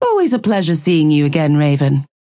Calico voice line - Always a pleasure seeing you again, Raven.